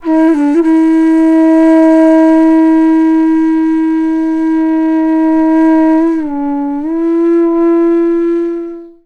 FLUTE-A05 -L.wav